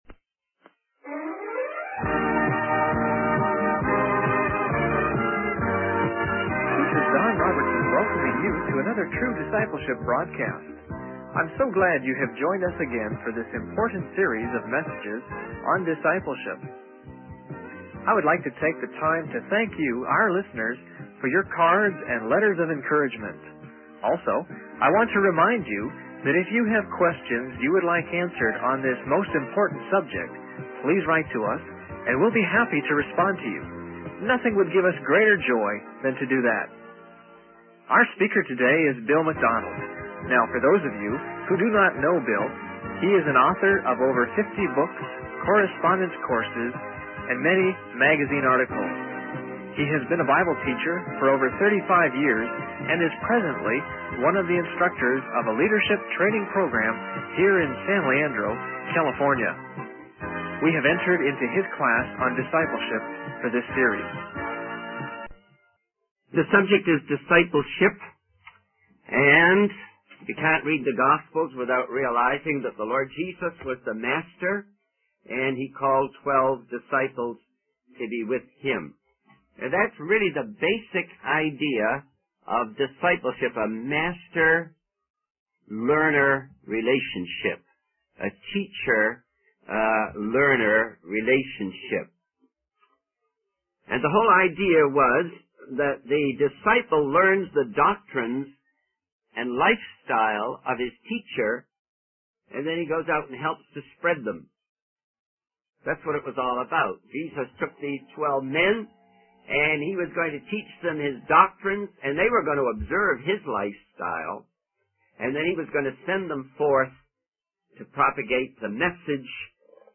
In this sermon, the speaker reflects on his past experiences of preaching and teaching discipleship.